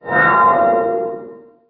Transcription: Howl